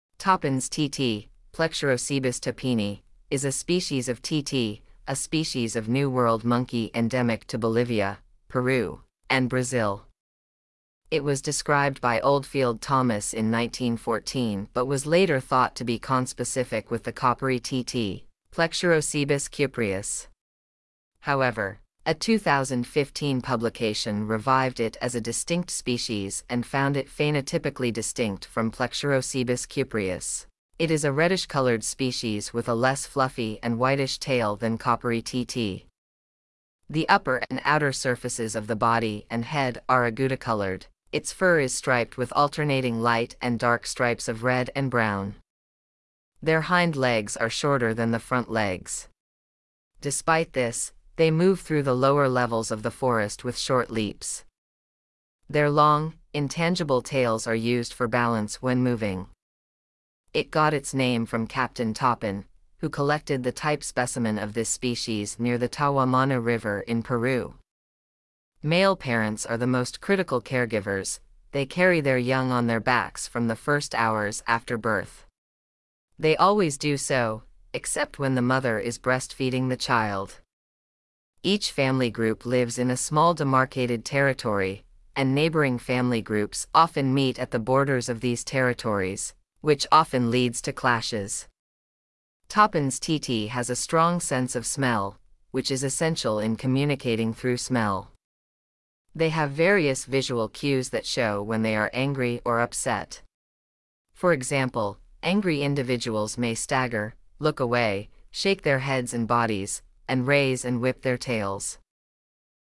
Toppin's Titi